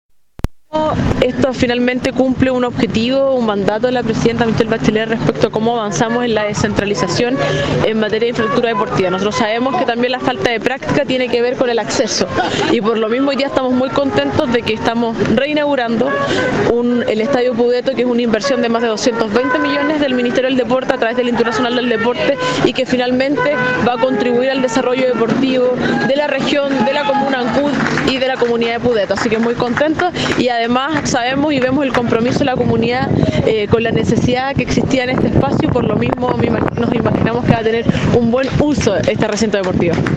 Bajo una lluvia permanente se desarrolló la ceremonia de inauguración del proyecto de mejoramiento del Estadio Pudeto en Ancud.
Al respecto, la Subsecretaria del Deporte, Nicole Sáez se refirió a la importancia de contar con este recinto para el desarrollo deportivo de la comuna y la región.